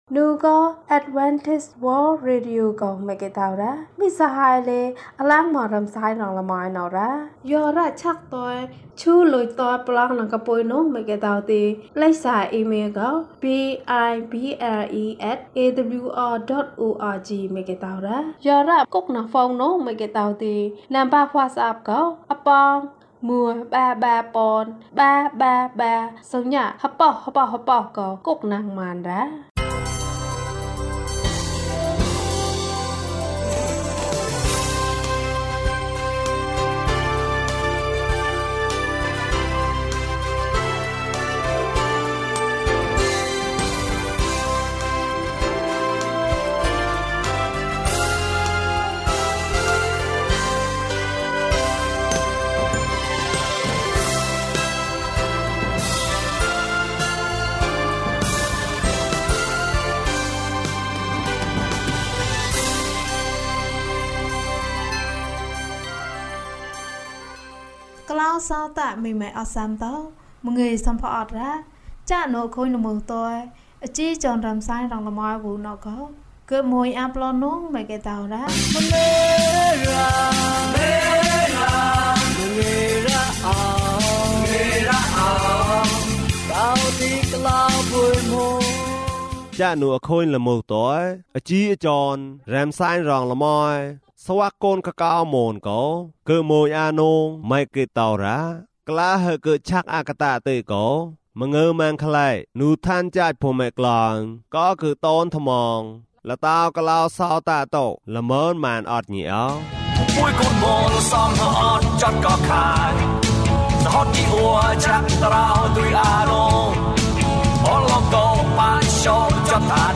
ယေရှုကယ်တင်ပါ။ ကျန်းမာခြင်းအကြောင်းအရာ။ ဓမ္မသီချင်း။ တရားဒေသနာ။